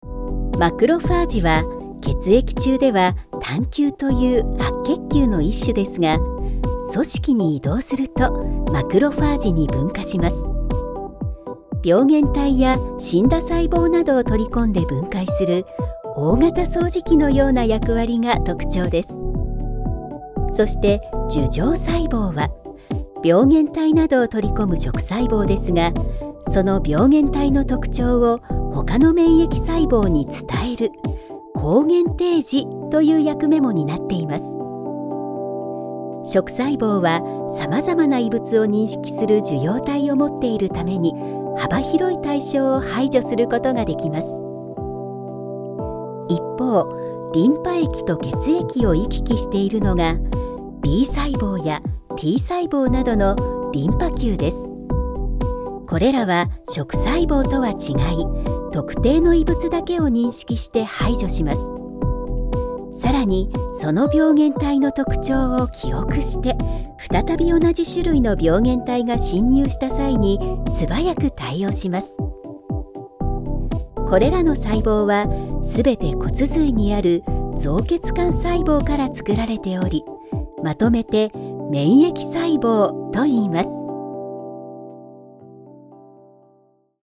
Narração Médica
Sua voz suave, clara e identificável é altamente versátil, tornando-a a escolha certa para várias grandes corporações e órgãos governamentais.
RodeNT1-A, Focusrite Scarlett 4i4, Audacity, Motor de som
Mezzo-sopranoSoprano